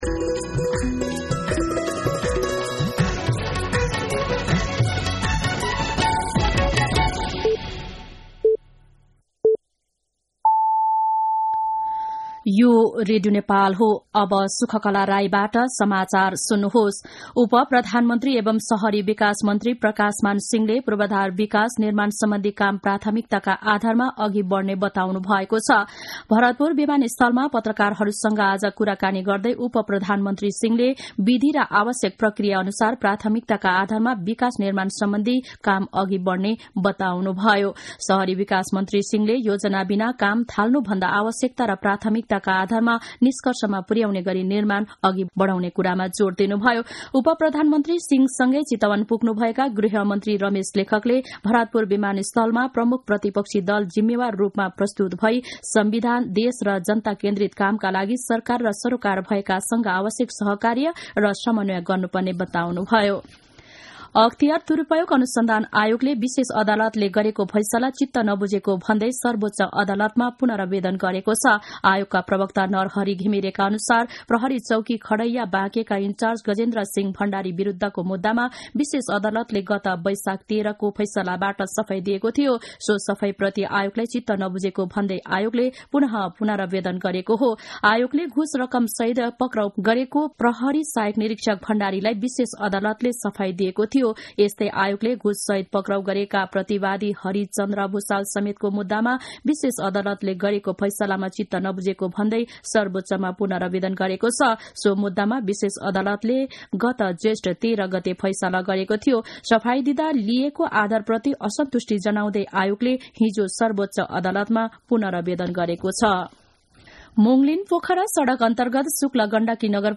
दिउँसो १ बजेको नेपाली समाचार : ३० मंसिर , २०८१
1-pm-Nepali-News-1.mp3